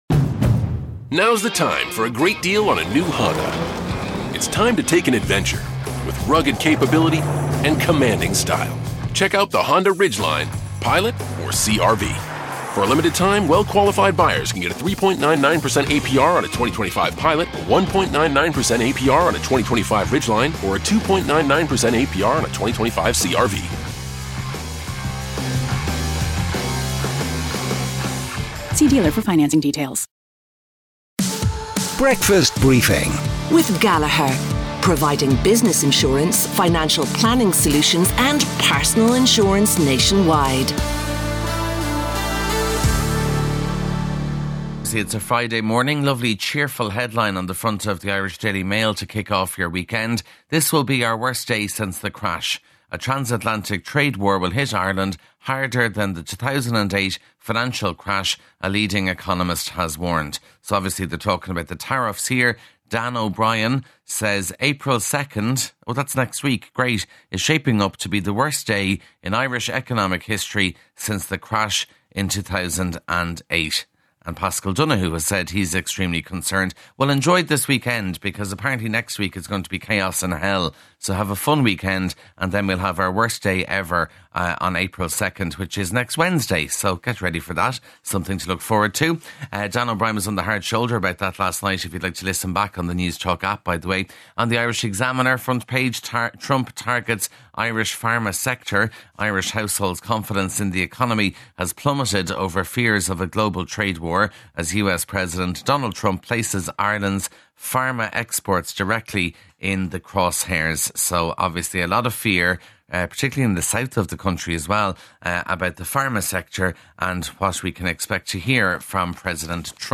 breakfast_briefing_newspaper_rev_efe14df9_normal.mp3